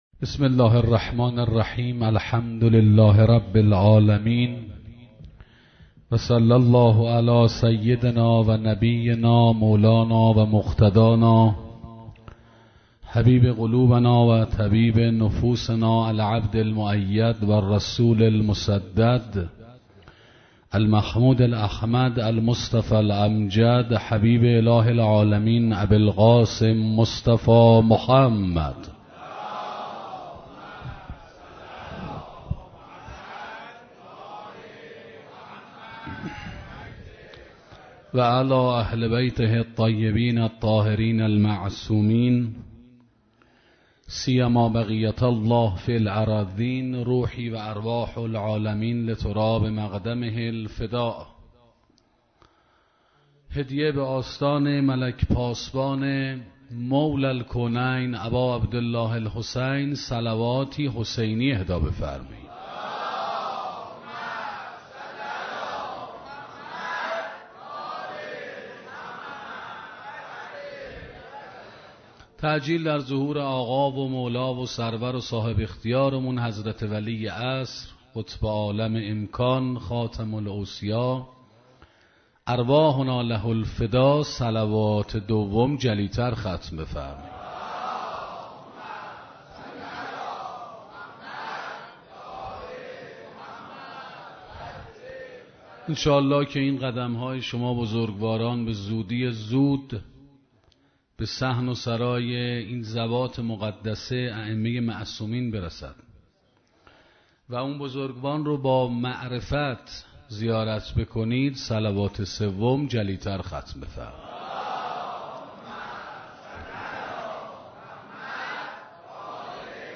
سخنرانی مُلک و ملکوت(زواره) 1 - موسسه مودت